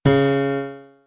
Piano Keys C Scale New